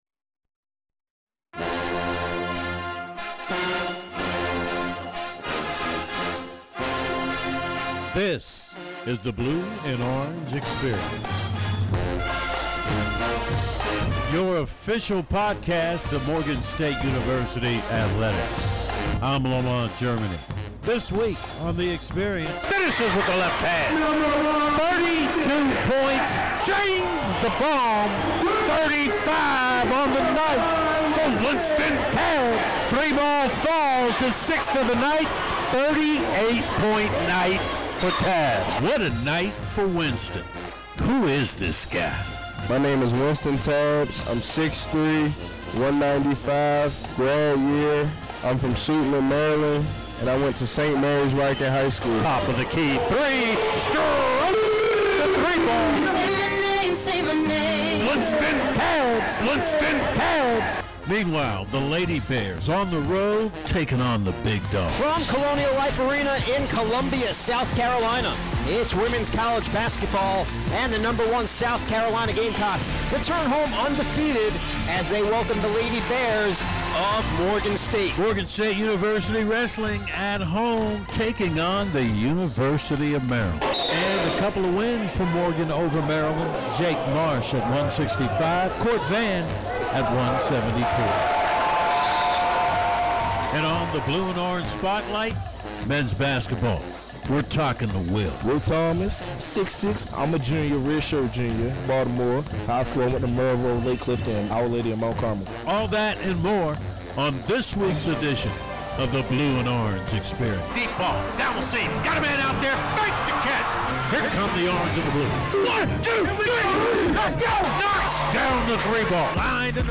It explores the teams. coaches, student-athletes, alumni, stories and traditions of Morgan State University athletics. This edition of the BLUE & ORANGE Experience looks back on the week in Morgan State athletics and features an interview